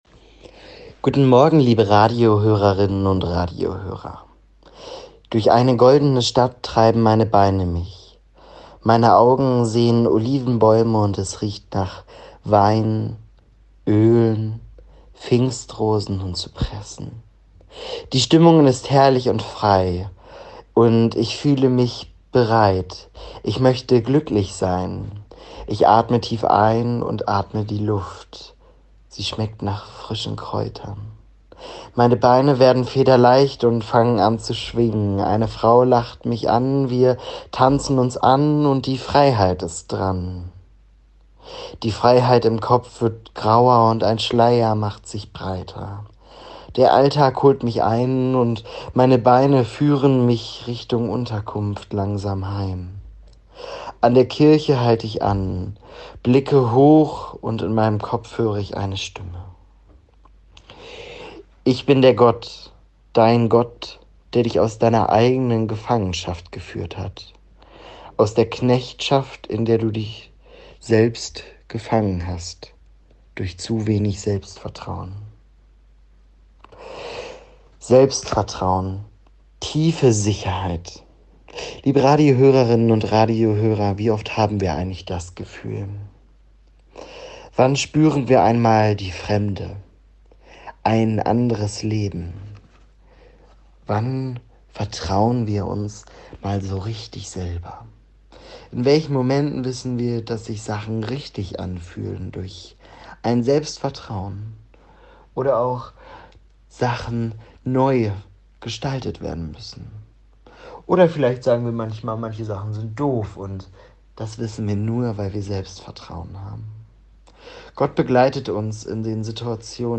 Radioandacht vom 31. Juli